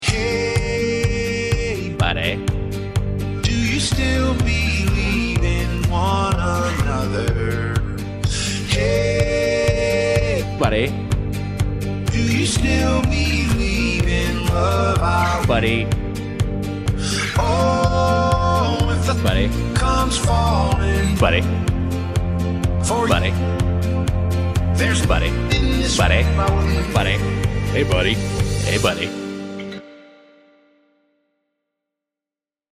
Doughboys is a podcast about chain restaurants. In each episode, they play a listener-submitted audio clip that is usually a mashup or remix of audio from previous episodes.